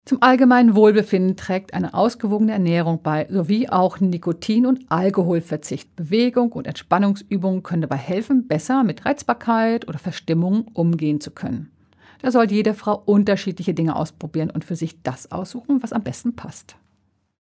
Radio O-Töne